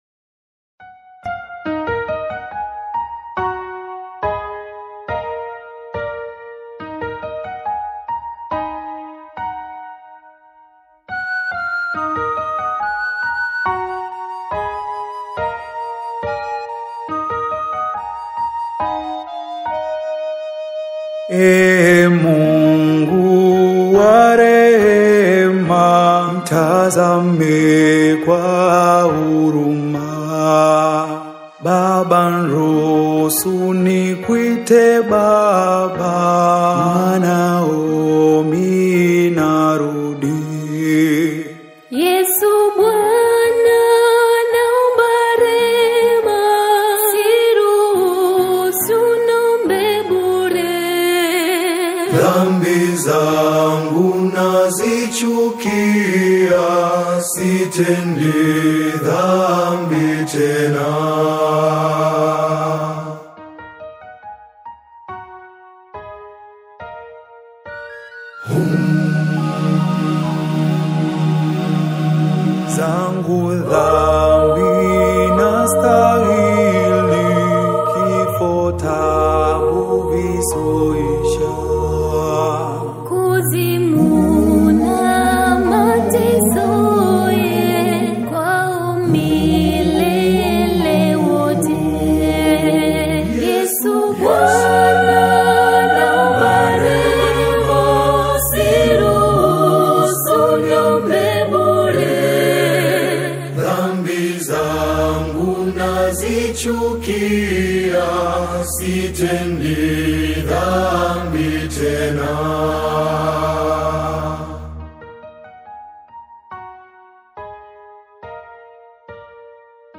The exquisitely arranged and vocally seamless single